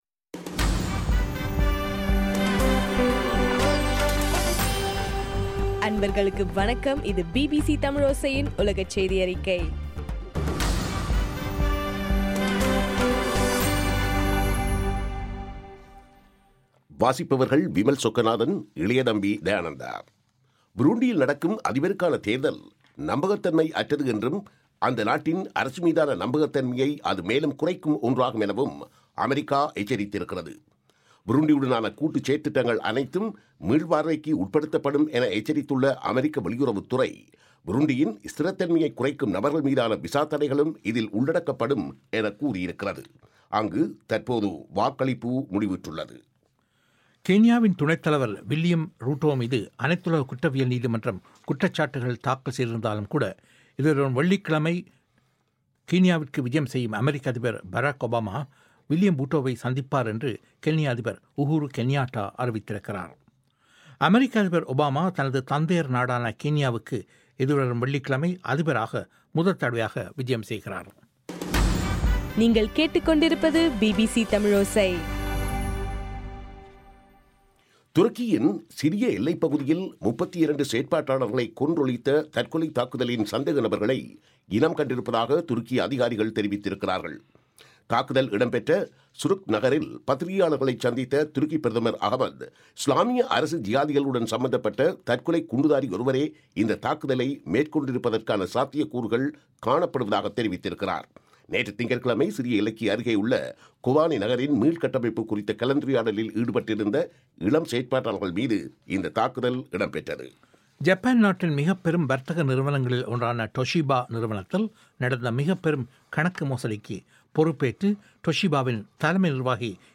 ஜூலை 21 பிபிசியின் உலகச் செய்திகள்